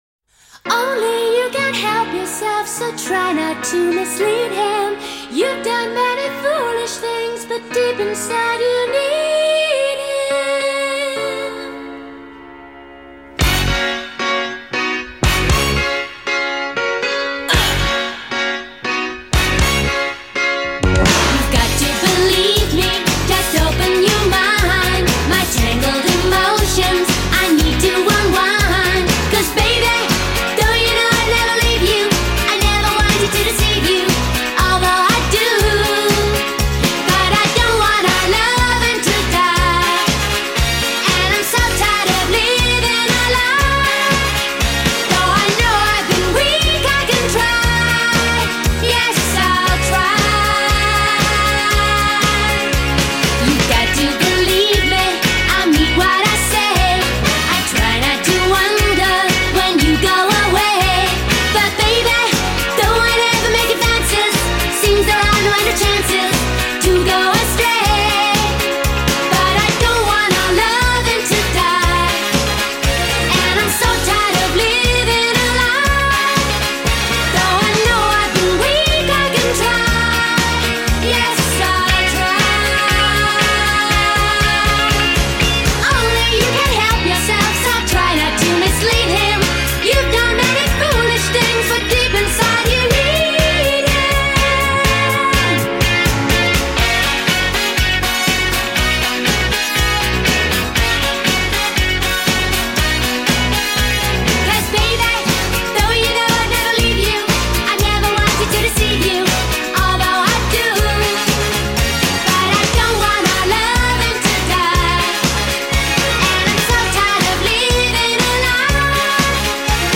this may be the finest retro girl pop ever produced